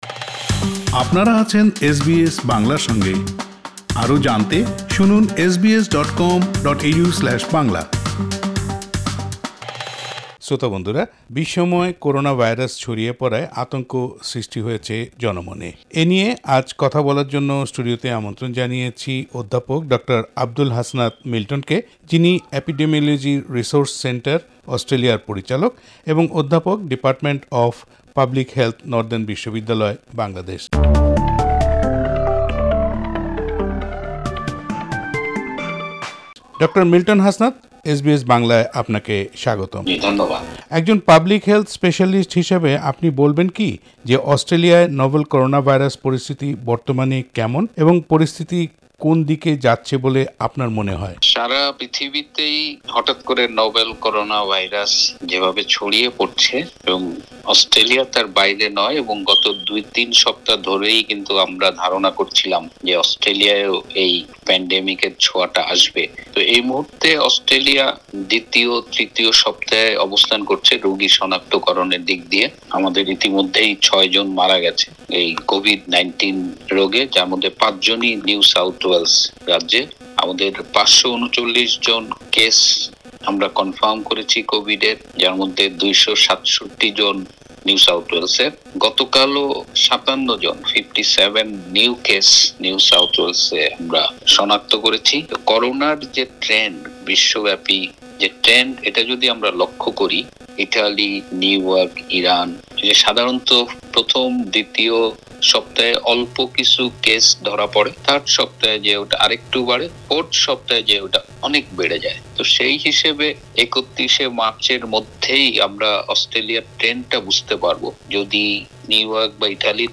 এসবিএস বাংলা